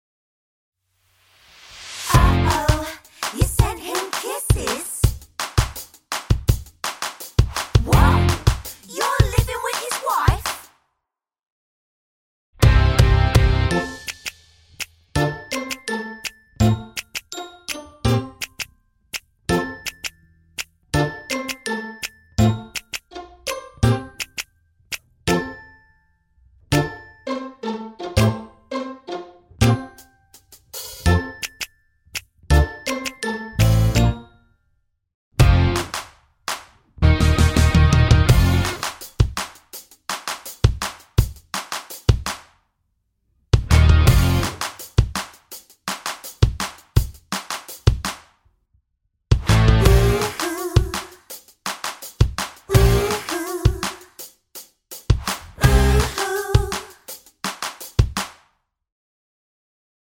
no Backing Vocals Musicals 4:07 Buy £1.50